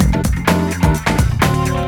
Yeah Brk 127-D.wav